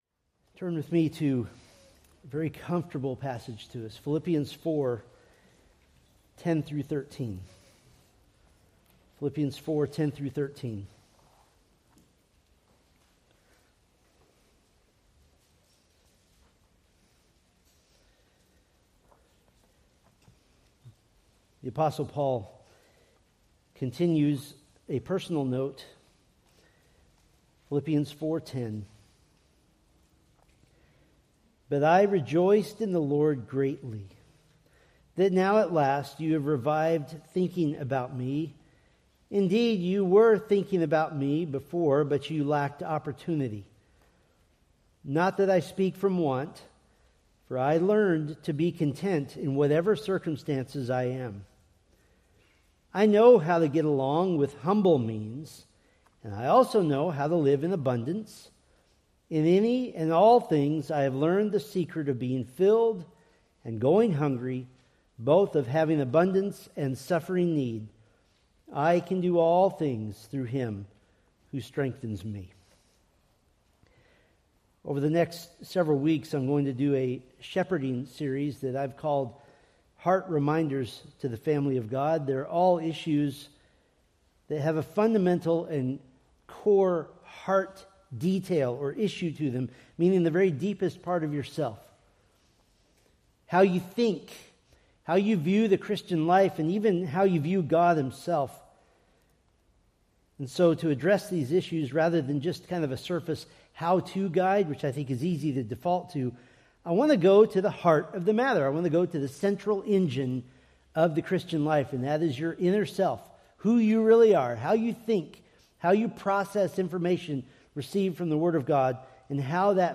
Preached April 26, 2026 from Philippians 4:10-13